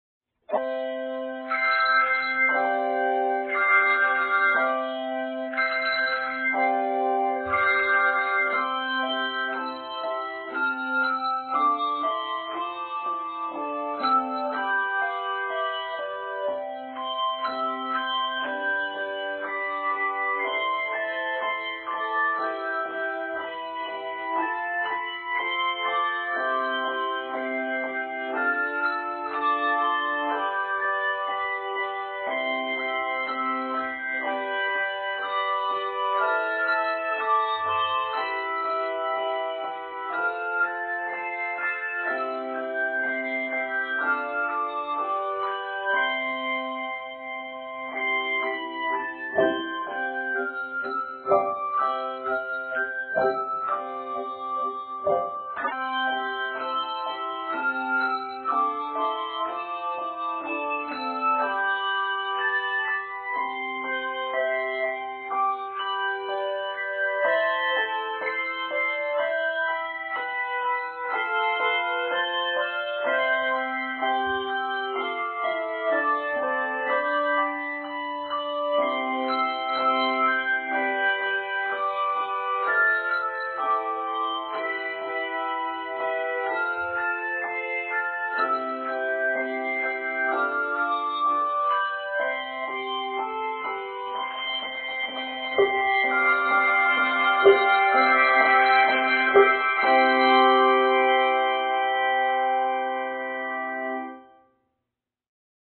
An original composition for 2-3 octaves